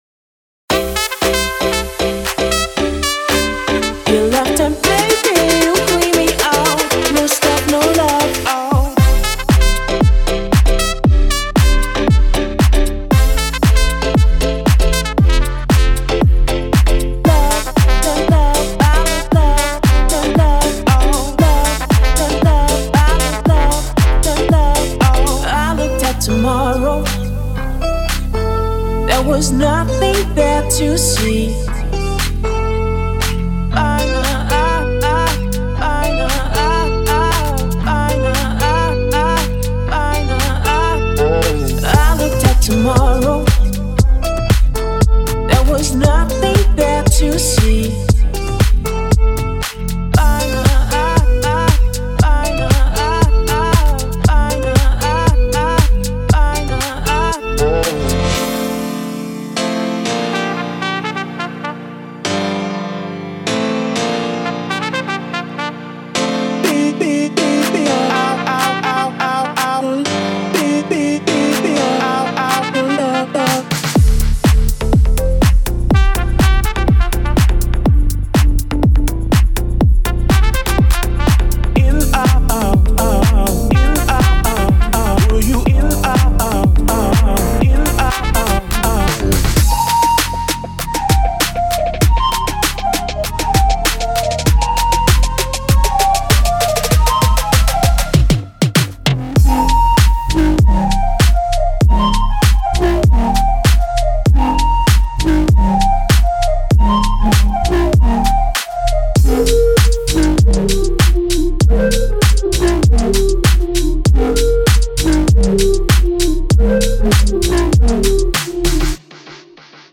厂牌电音采样包
音色试听